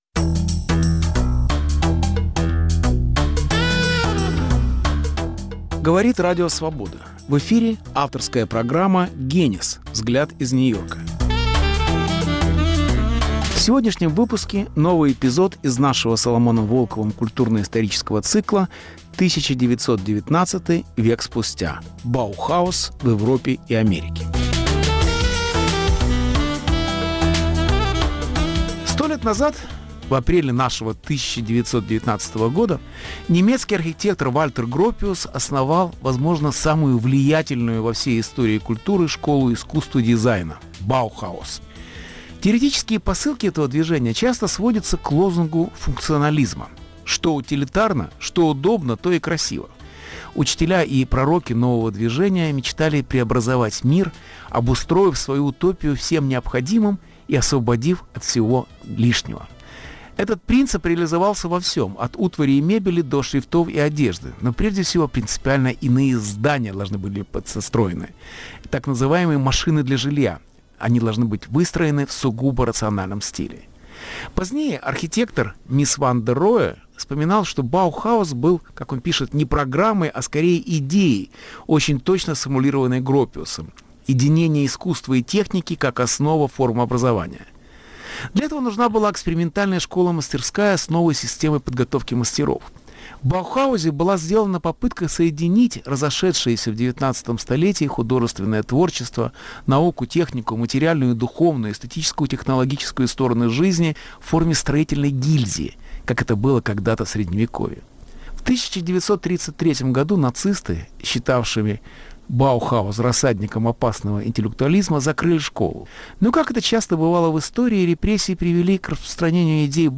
Беседа с Соломоном Волковым